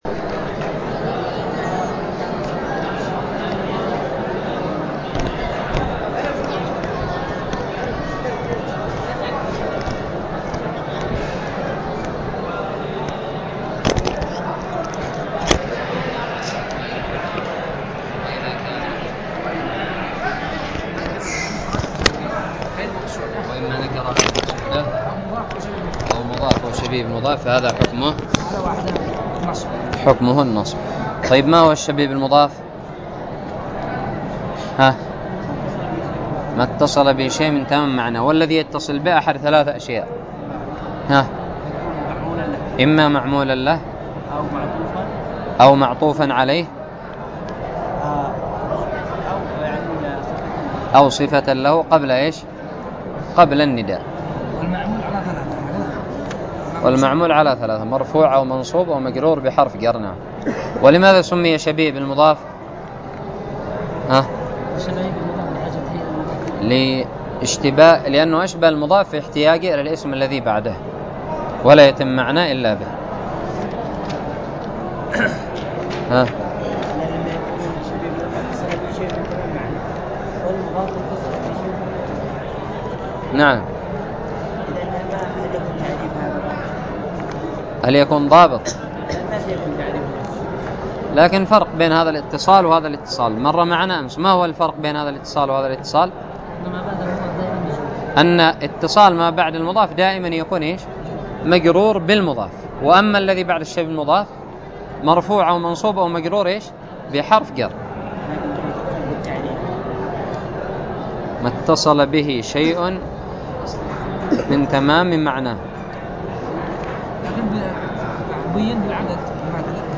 ألقيت بدار الحديث بدماج